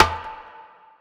SNARE_SWIZZ.wav